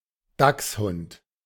The German word Dachshund is pronounced [ˈdaks.hʊnt]